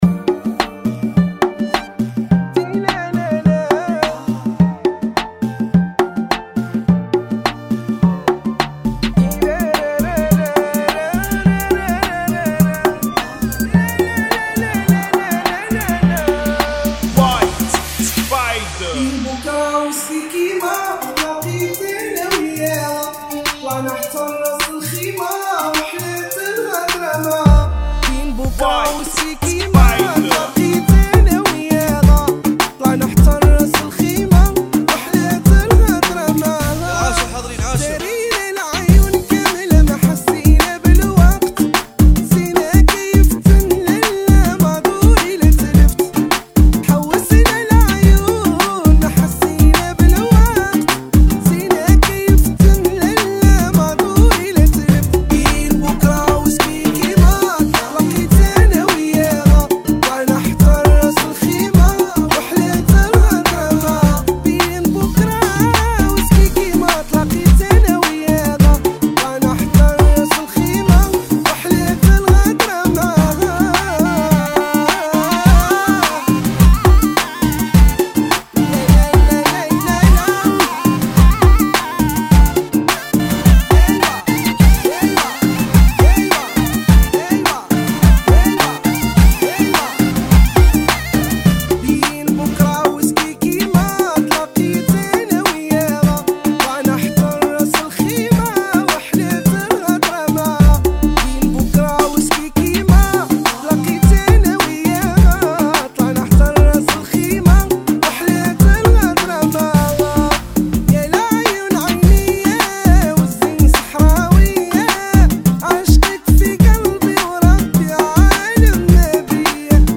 [ 105 Bpm ]